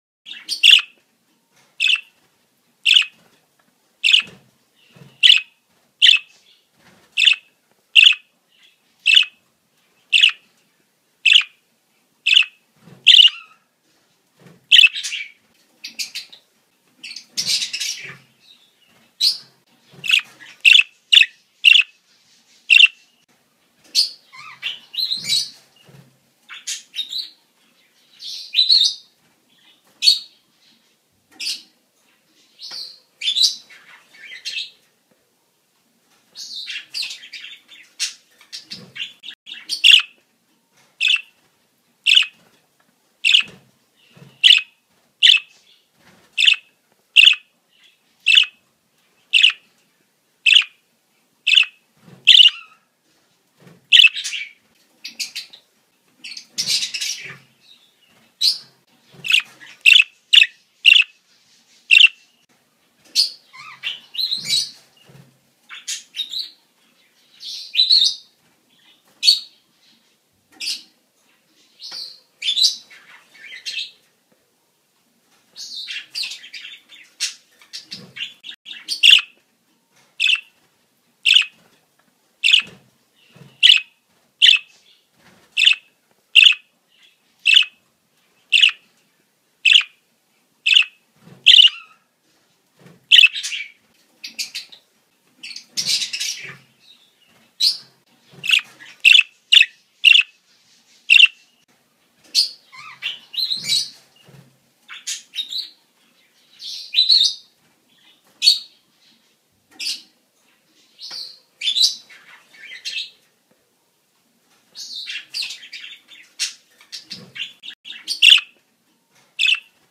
دانلود آهنگ مرغ عشق برای آموزش به جوجه مرغ عشق و تحریک آن برای چهچه زدن از افکت صوتی انسان و موجودات زنده
دانلود صدای مرغ عشق برای آموزش به جوجه مرغ عشق و تحریک آن برای چهچه زدن از ساعد نیوز با لینک مستقیم و کیفیت بالا